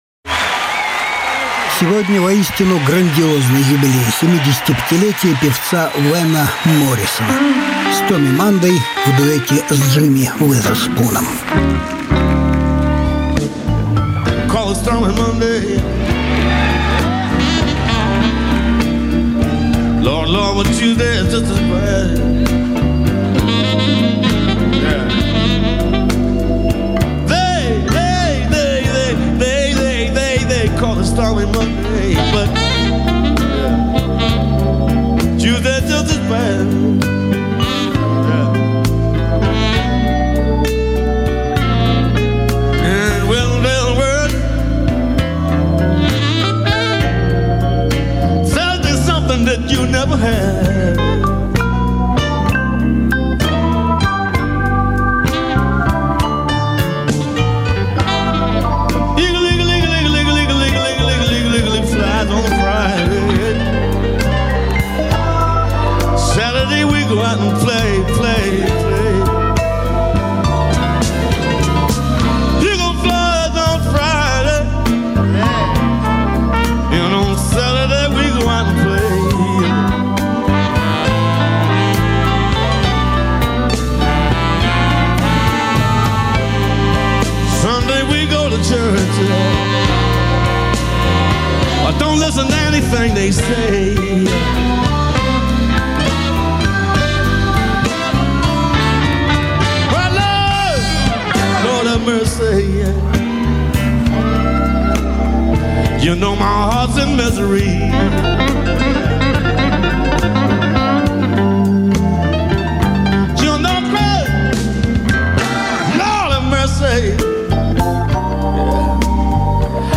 Альбом: Различные альбомы Жанр: Блюзы и блюзики СОДЕРЖАНИЕ 31.08.2020 1.